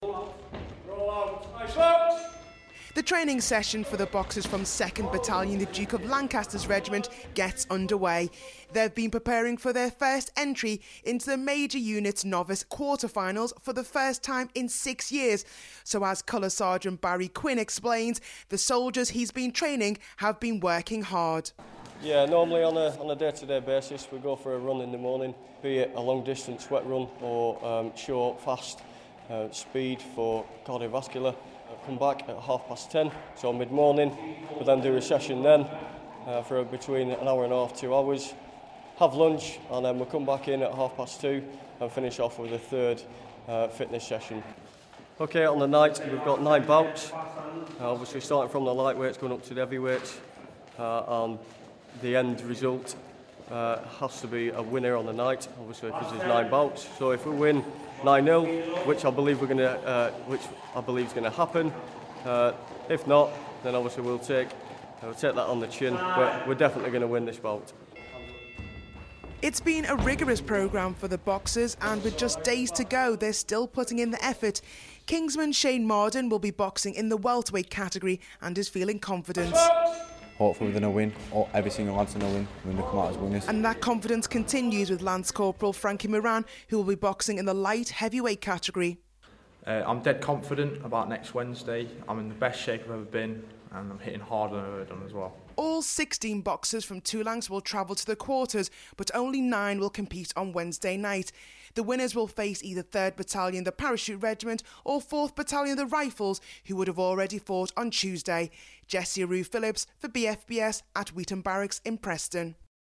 The quarter-finals of the Army's Major Units Novice Boxing championships gets underway for 2015. I went along to Weeton Barracks in Preston to watch boxers from 2nd Battalion The Duke of Lancaster's Regiment ahead of their fight with 13 Air Assault Battalion.